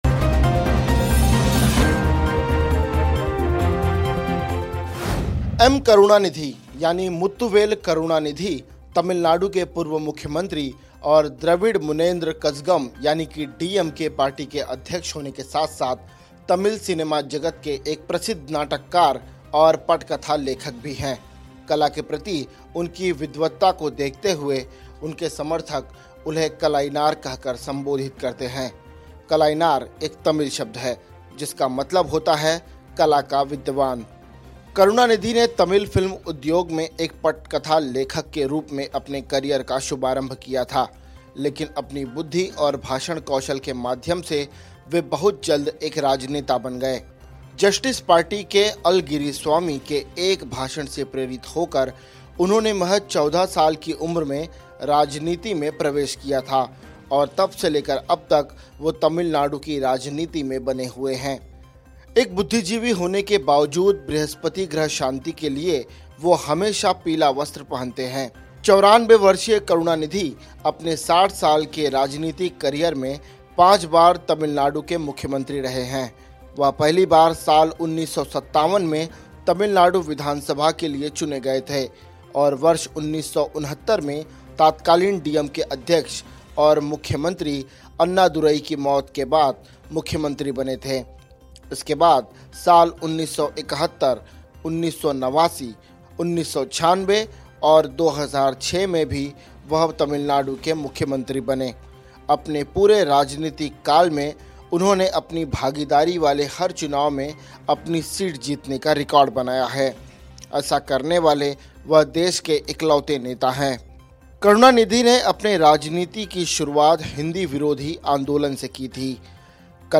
न्यूज़ रिपोर्ट - News Report Hindi / करुणानिधि का पूरा राजनीतिक सफर, इस कारण हमेशा पहनते हैं पीला वस्त्र ! karunanidhi